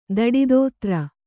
♪ daḍi dōtra